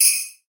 check-on.wav